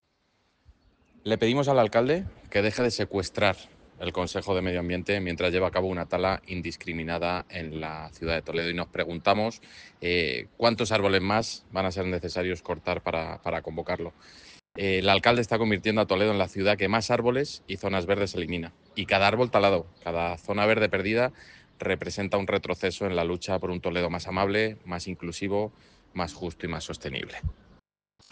PSOE-Audio-concejal-Pablo-Garcia.mp3